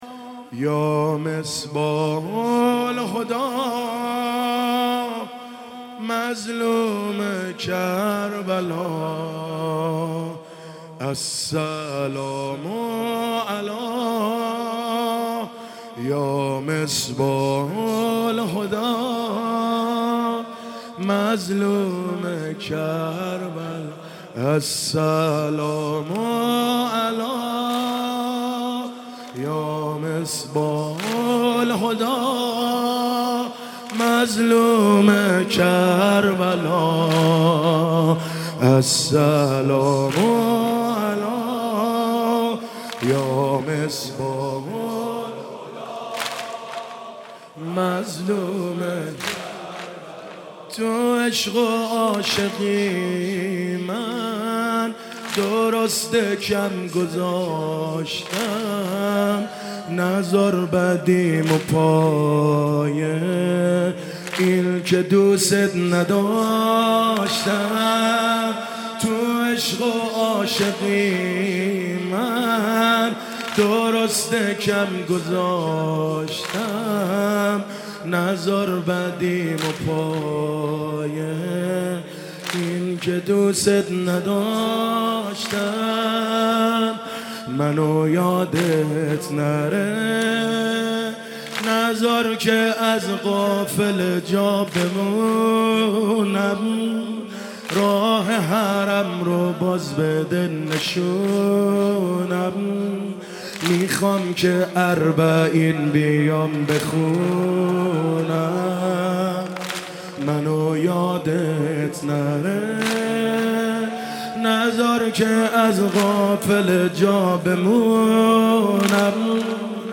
شب ششم محرم95 /هيئت الرضا(ع)
سینه زنی